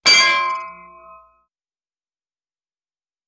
Звуки металлических ударов
Звук столба ударяющего о железо в мультфильме